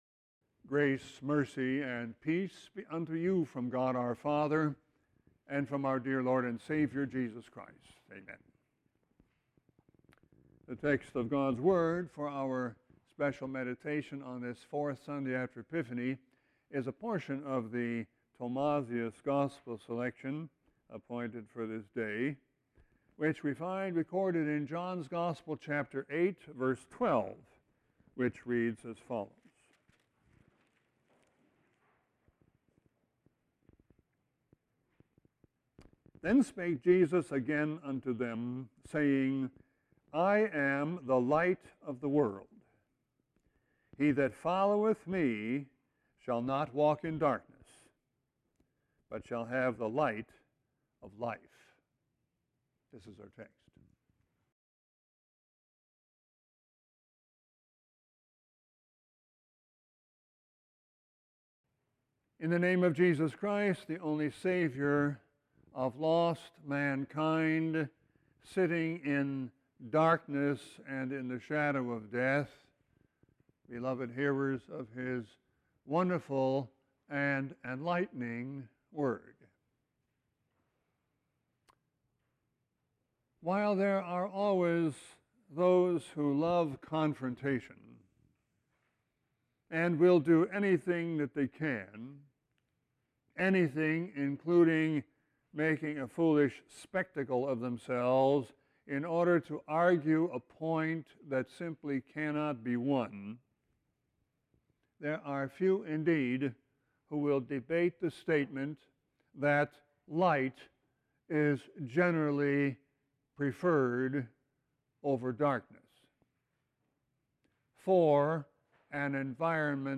Sermon 1-29-17.mp3